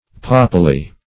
Meaning of papally. papally synonyms, pronunciation, spelling and more from Free Dictionary.
papally - definition of papally - synonyms, pronunciation, spelling from Free Dictionary Search Result for " papally" : The Collaborative International Dictionary of English v.0.48: Papally \Pa"pal*ly\, adv.